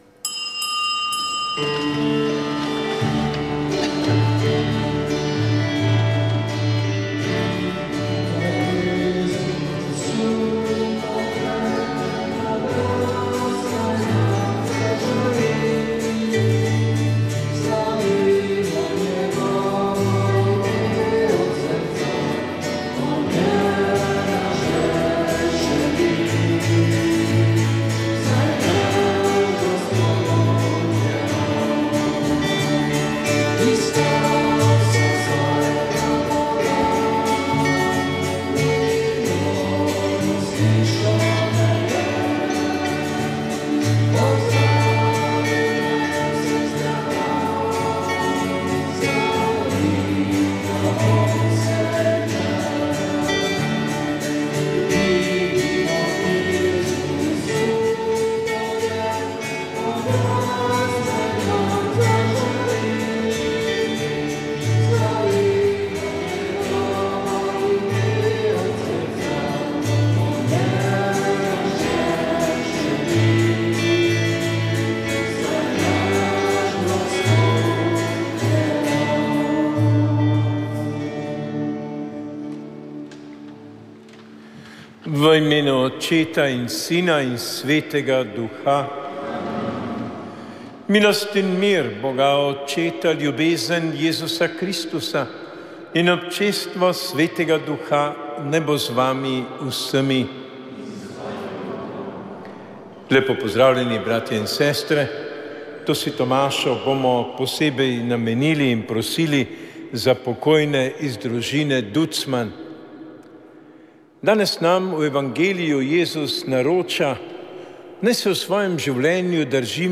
Sveta maša
Sv. maša iz cerkve Marijinega oznanjenja na Tromostovju v Ljubljani 12. 3.